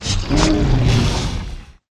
Index of /client_files/Data/sound/monster/dx1/
dx1_sandboss2_idle.ogg